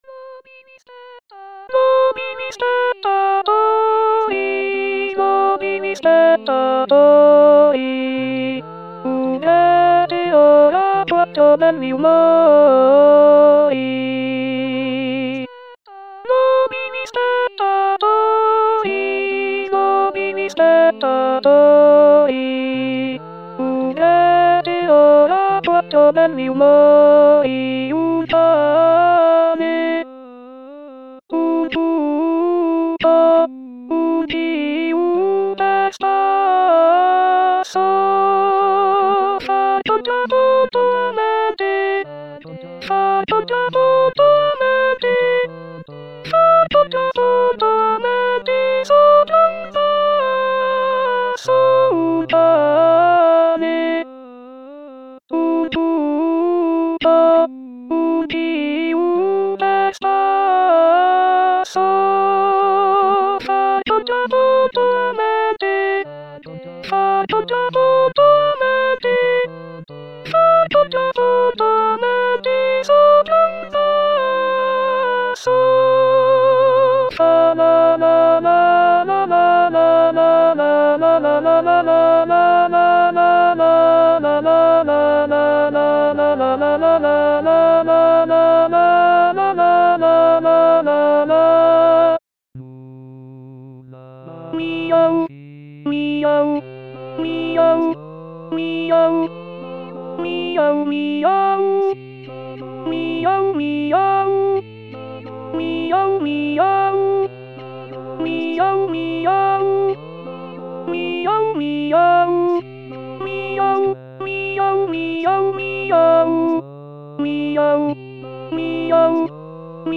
Contrepoint - Alto Alto (08/01/2016)
contrepoint-chr_alto.mp3